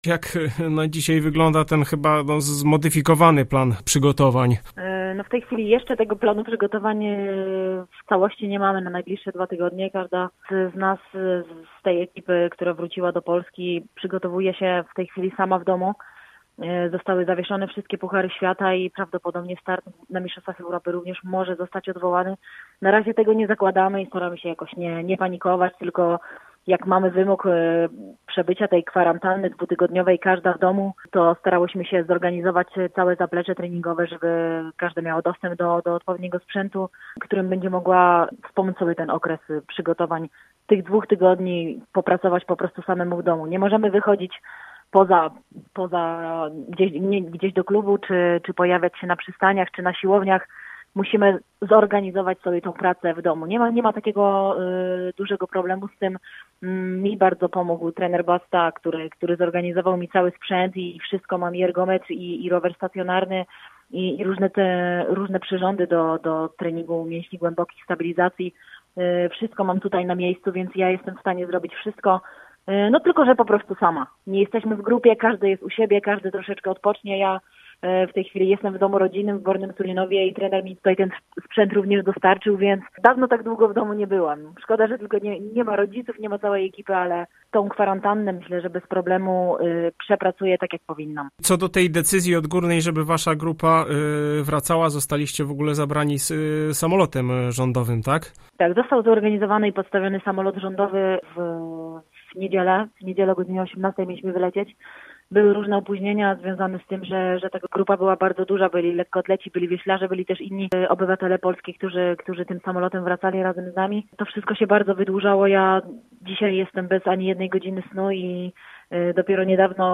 O obecnej sytuacji z zawodniczką AZS AWF Gorzów rozmawiał